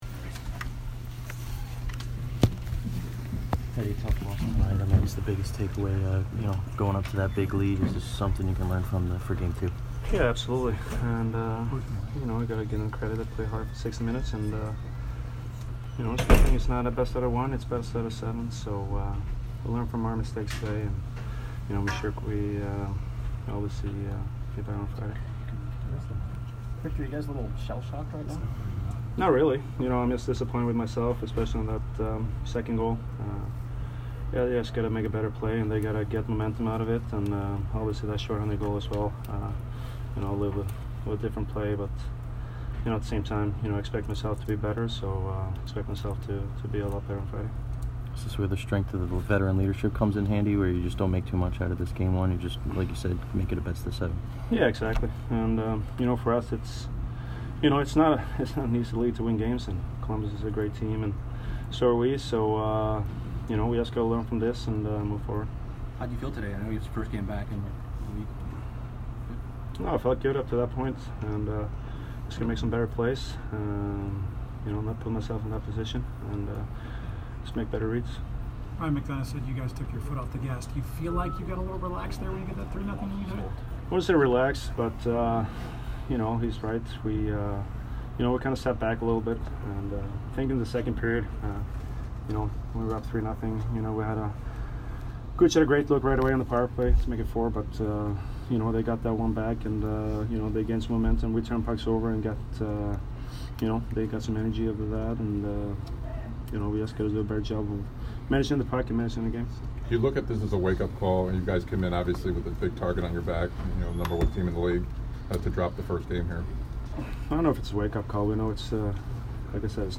Victor Hedman post-game 4/10